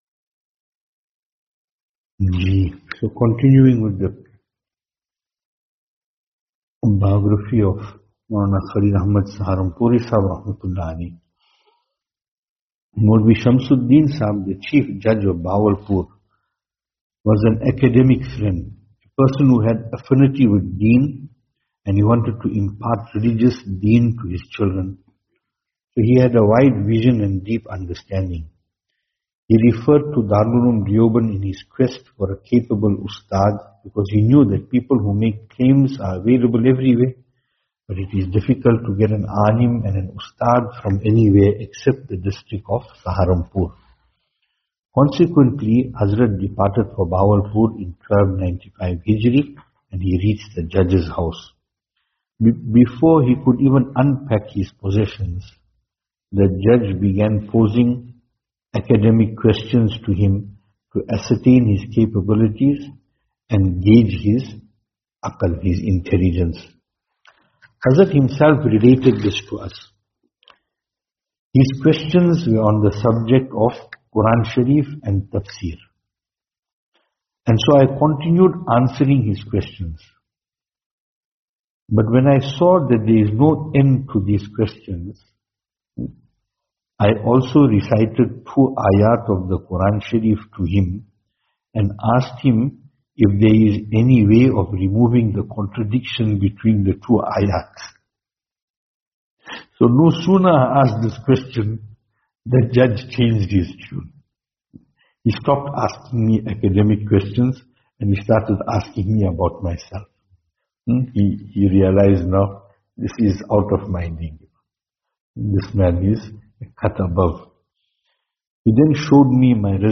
Venue: Albert Falls , Madressa Isha'atul Haq Service Type: Majlis